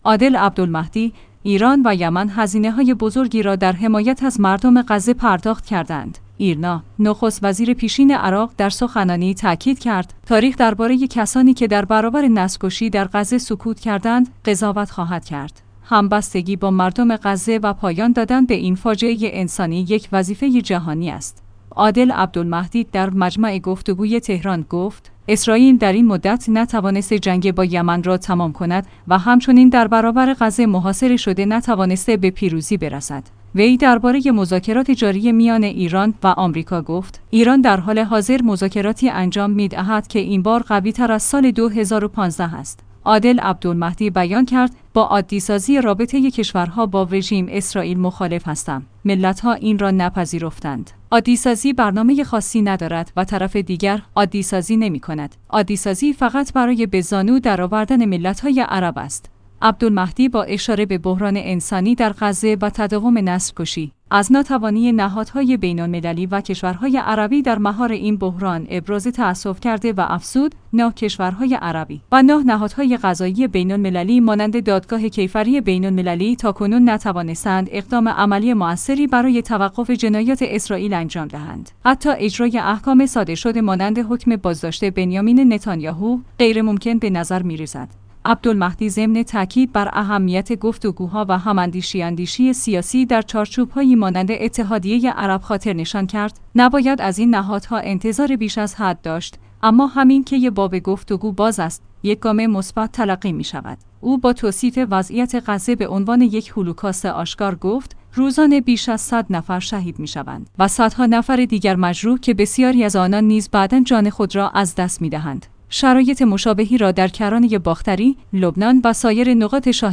ایرنا/ نخست‌وزیر پیشین عراق در سخنانی تأکید کرد: تاریخ درباره کسانی که در برابر نسل‌کشی در غزه سکوت کردند قضاوت خواهد کرد. همبستگی با مردم غزه و پایان دادن به این فاجعه انسانی یک وظیفه جهانی است.